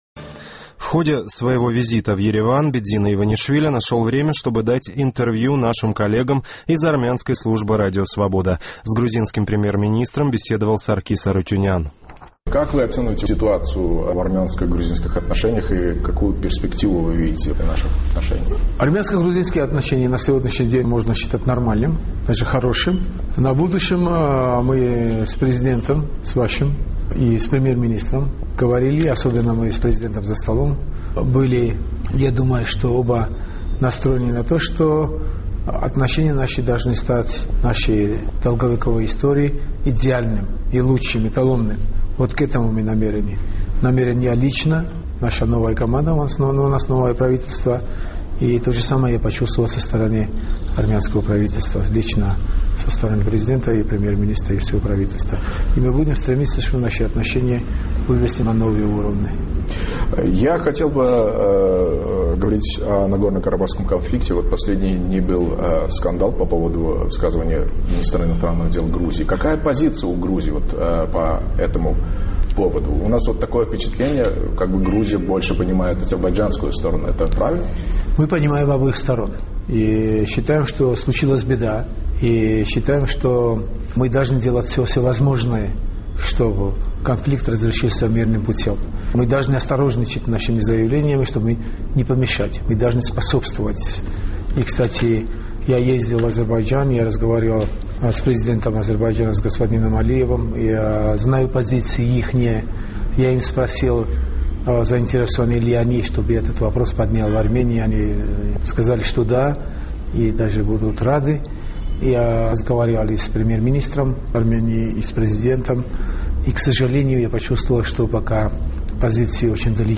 В ходе визита в Ереван премьер-министр Грузии Бидзина Иванишвили нашел время, чтобы дать интервью армянской службе Радио Свобода.